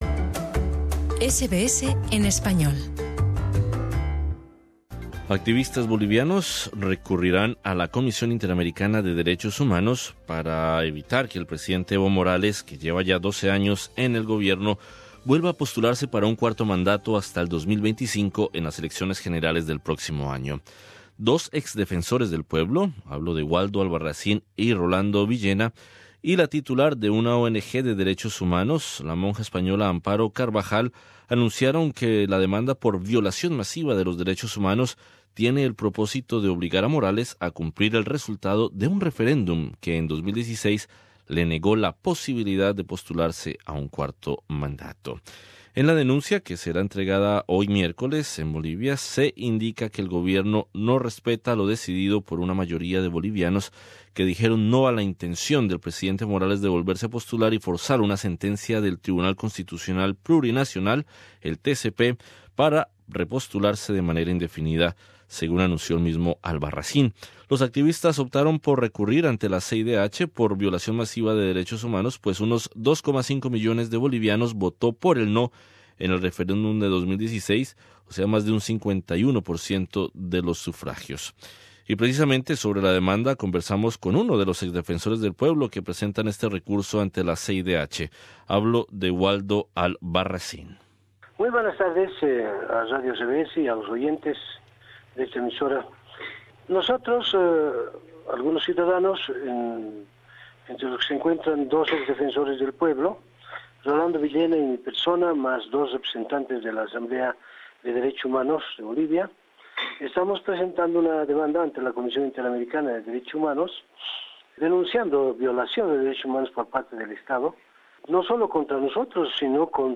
Entrevista con Waldo Albarracín.